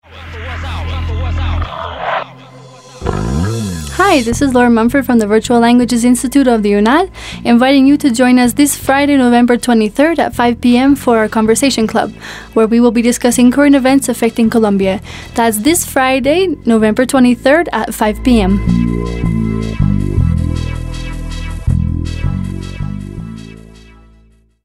Escuchar la promoción del evento del taller de inglés. 22 de noviembre de 2012